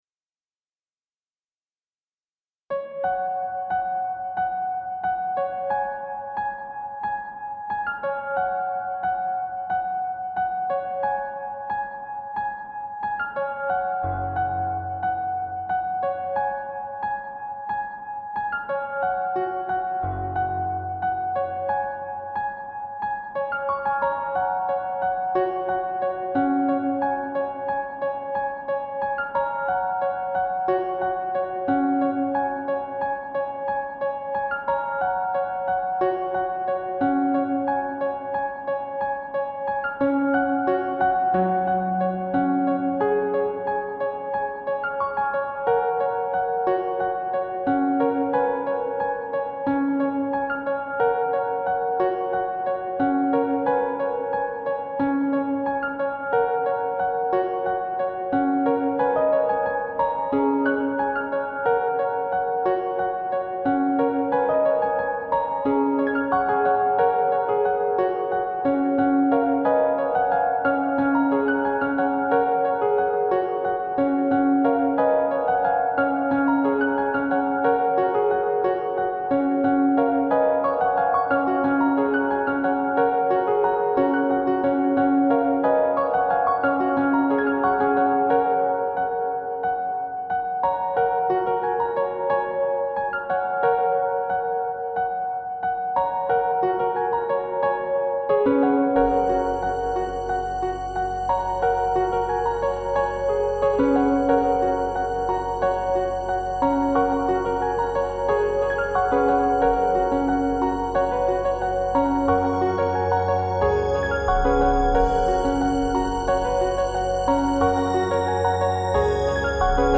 Techno
Trance
Electro